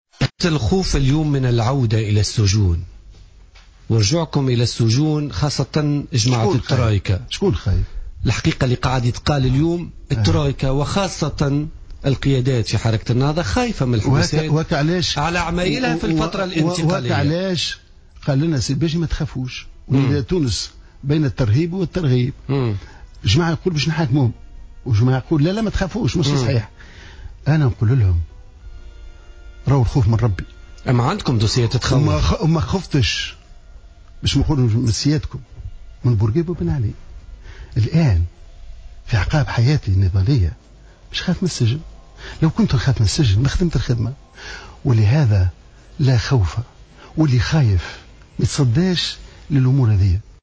انتقد حمادي الجبالي، ضيف حصة الاربعاء 17 ديسمبر من برنامج بوليتيكا على إذاعة الجوهرة، ازدواجية خطاب قيادات حركة نداء تونس والتي تراوحت بين الترغيب والترهيب.